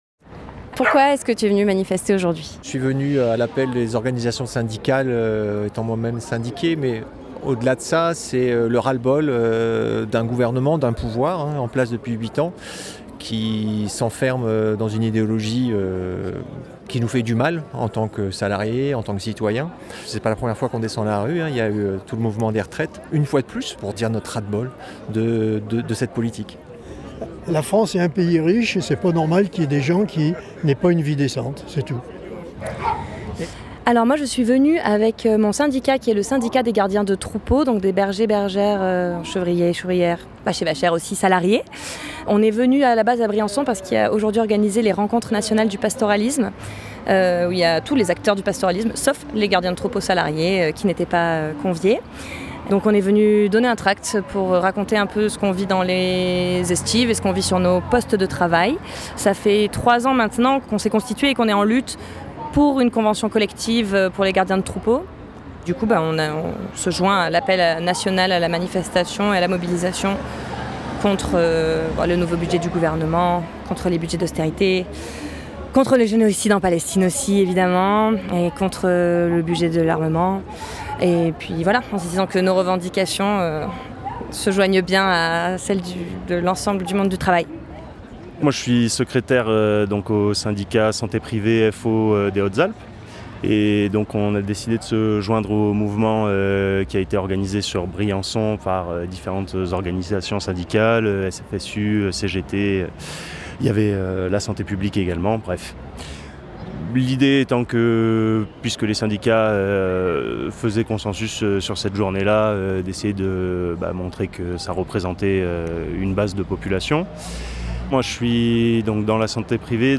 Pour la cité Vauban, le rendez-vous était donné place de l'Europe pour une mobilisation sans défilé. Micro-trottoir auprès des participant.e.s. Pourquoi sont-ils venus manifester ?